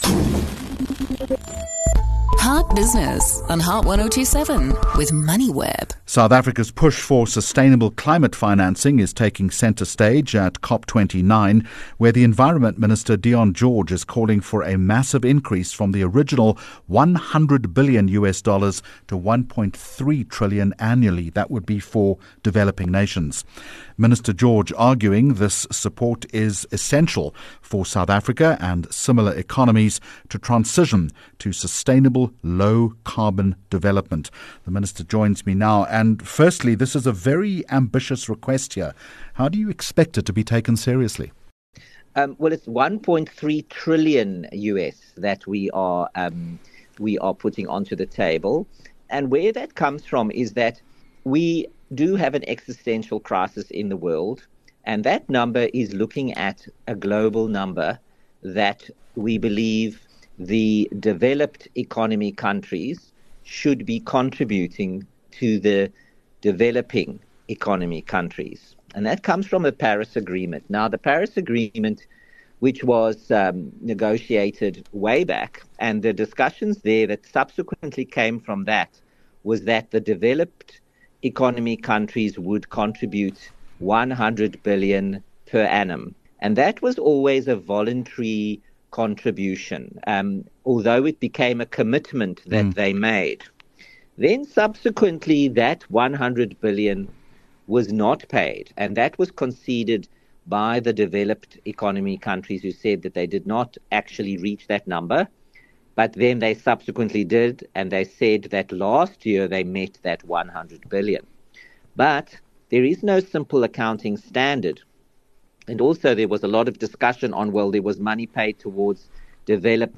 EXPERT Topic: Trillions needed for SA to meet climate goals Guest: Dion George -Minister Dion George, Minister of Environment, Forestry and Fisheries